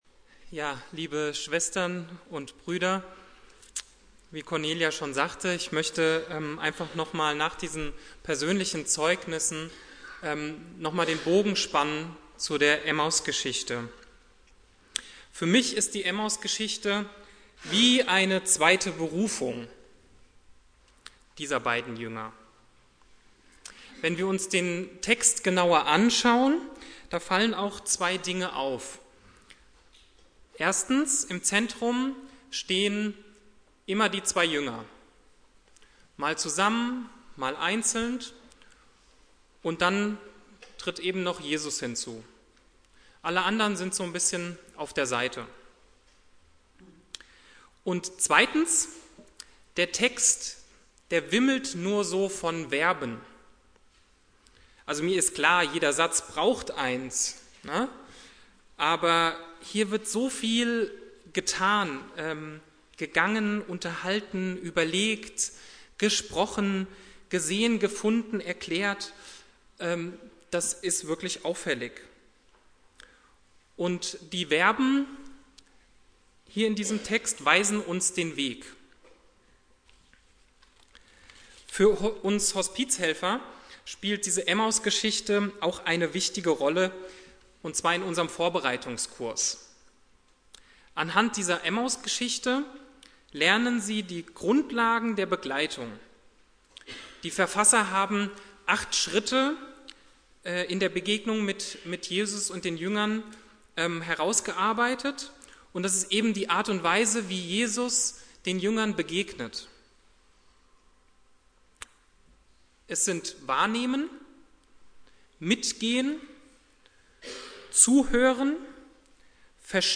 Finde weitere Predigten...